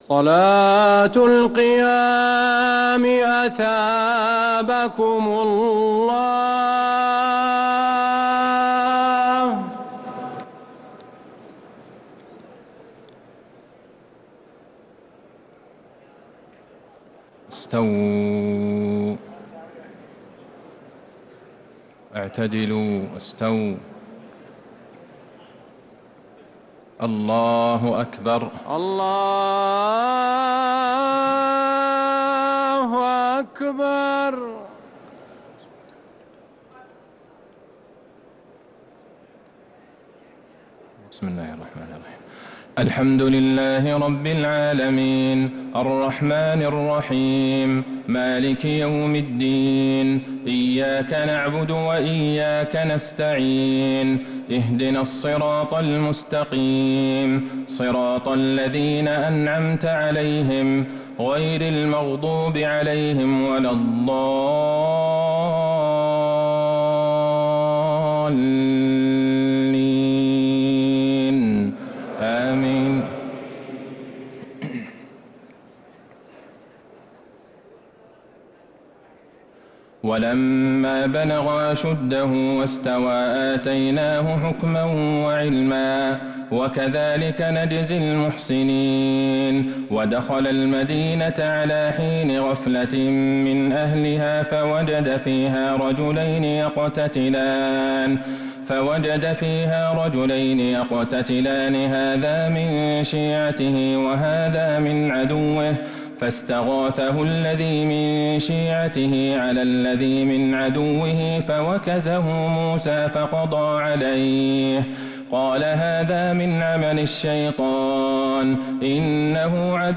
تهجد ليلة 23 رمضان 1447هـ من سورة القصص (14-88) | Tahajjud 23rd night Ramadan 1447H Surah Al-Qasas > تراويح الحرم النبوي عام 1447 🕌 > التراويح - تلاوات الحرمين